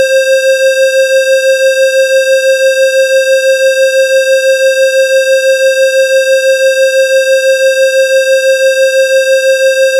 536Hzの波形の高さを、530Hzの波形の半分にして合成した結果が、以下の音声です。
上段：530Hz, 中段：振幅半分の536Hz, 下段：合成後の波形
こちらのほうが、うなりが弱い音になっています。